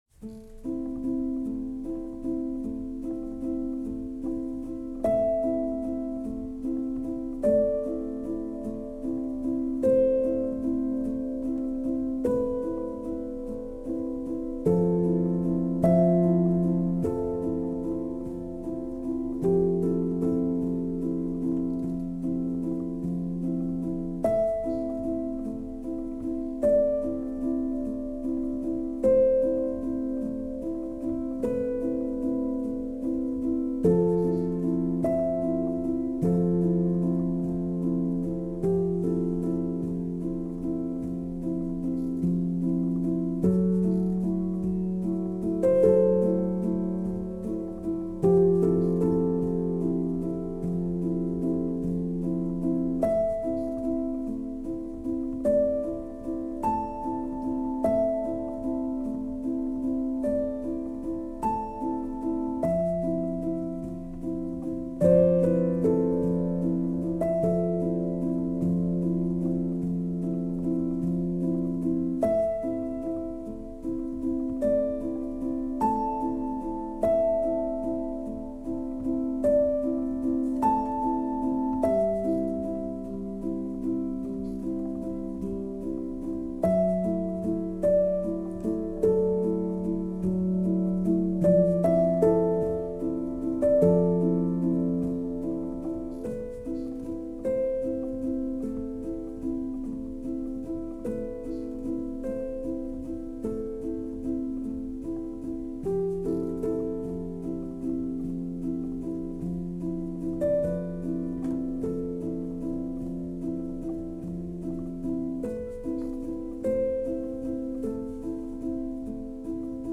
a modern classical / classical crossover solo piano single